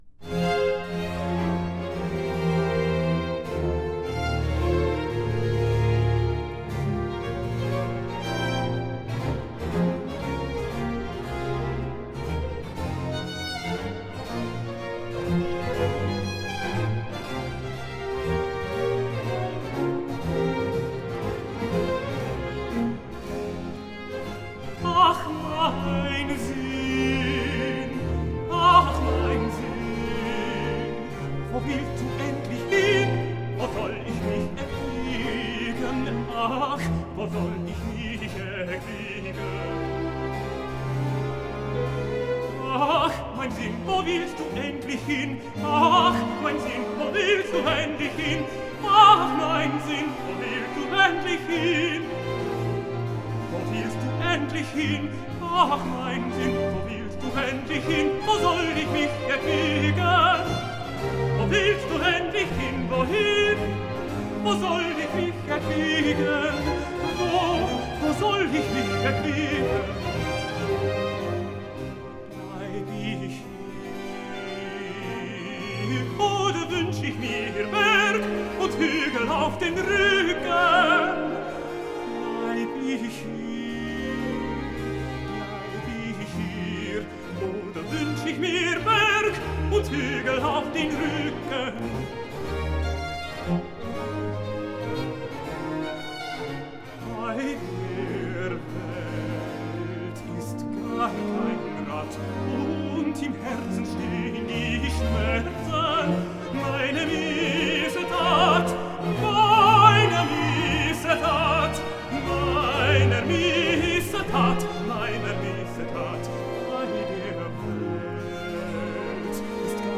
Aria (Tenor)